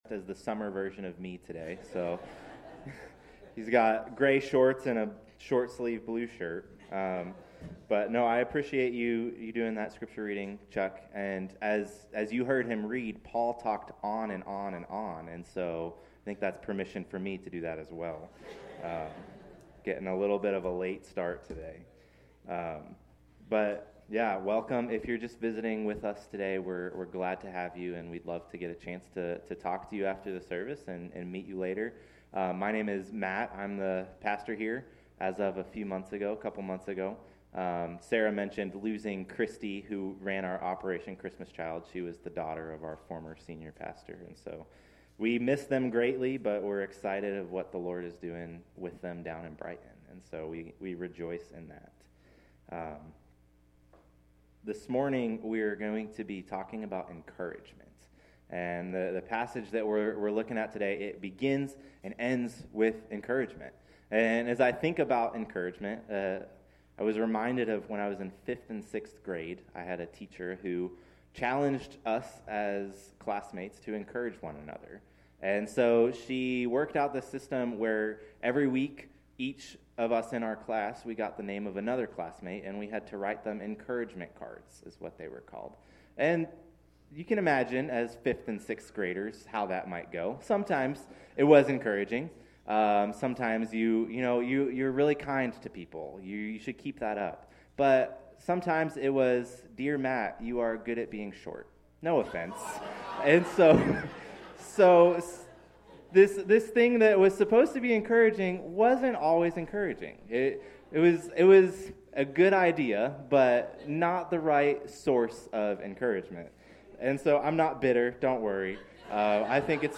October-5-Sermon-Audio.mp3